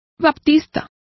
Complete with pronunciation of the translation of baptists.